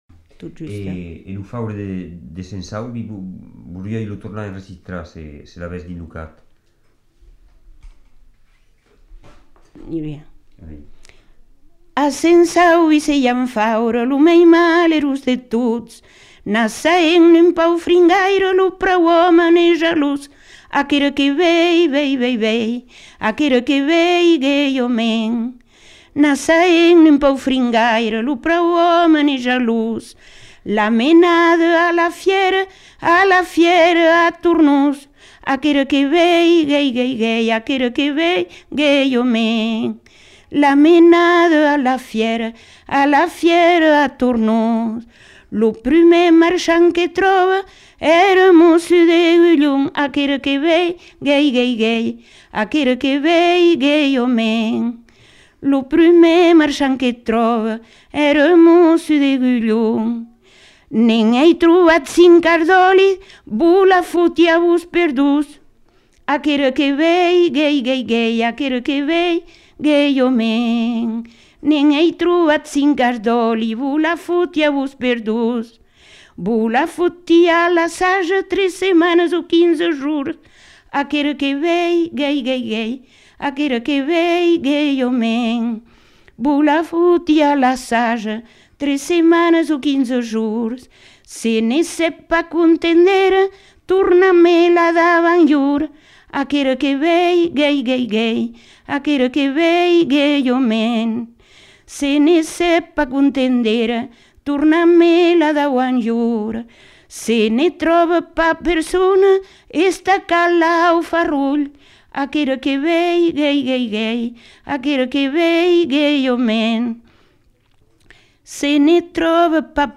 Aire culturelle : Marmandais gascon
Lieu : Tonneins
Genre : chant
Effectif : 1
Type de voix : voix de femme
Production du son : chanté
Danse : rondeau
Ecouter-voir : archives sonores en ligne